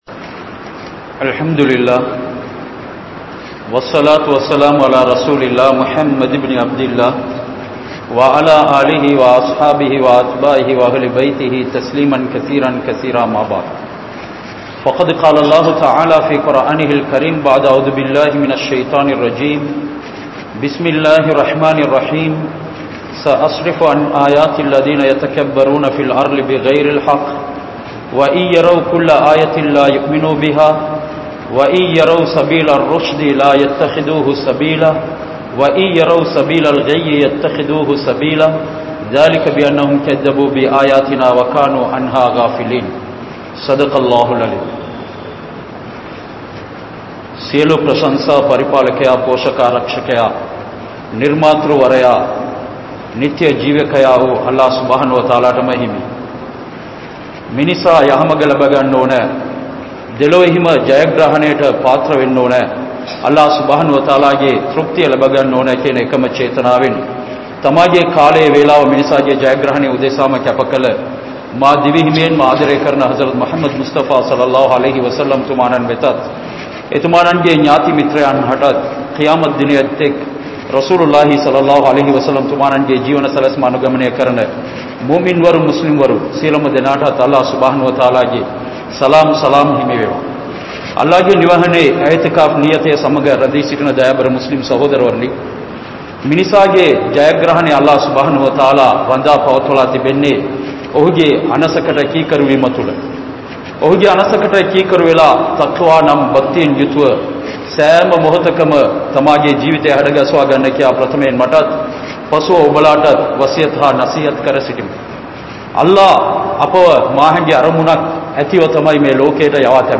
Paaraihalaaha Maarivitta Ullangal (பாறைகளாக மாறிவிட்ட உள்ளங்கள்) | Audio Bayans | All Ceylon Muslim Youth Community | Addalaichenai